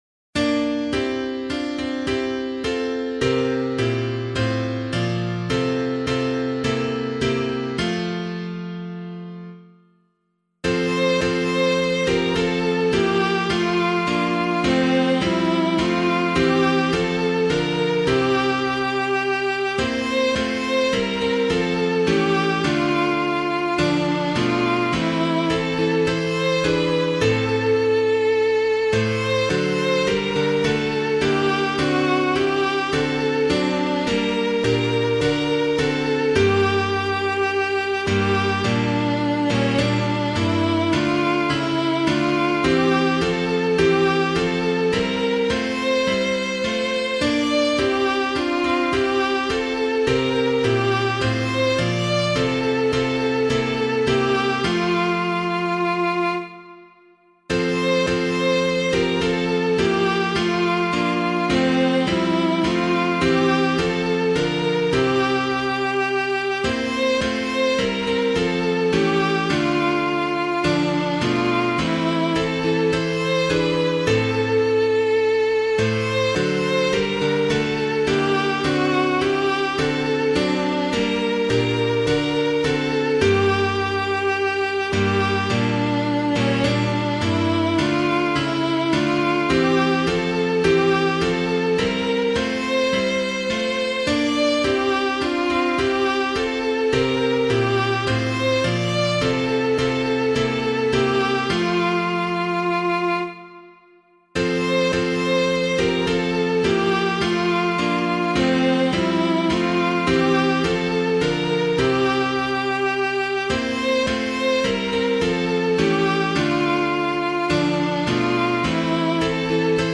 Hymn of the Day:  2nd Sunday of Advent, Year B
piano